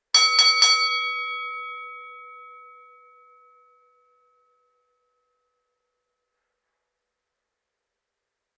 boxing-bell-01-3strike.ogg